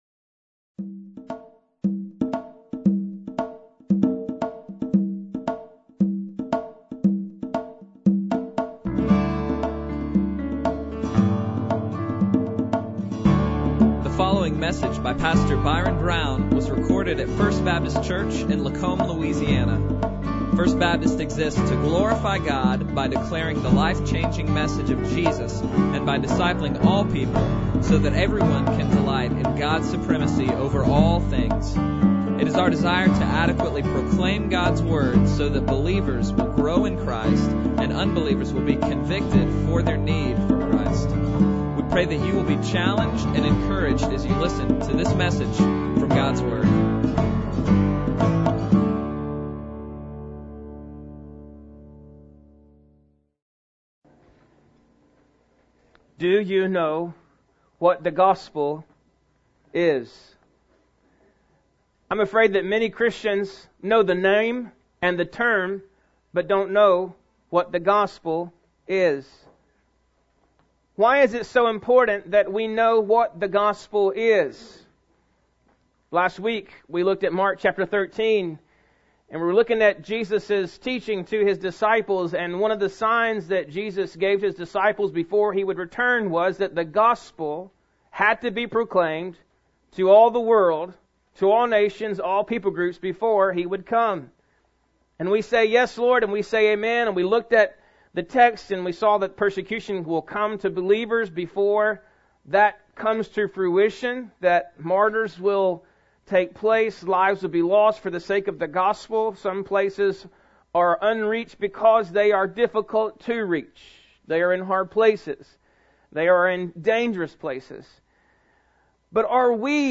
Bible Text: Mark 13:10 | Preacher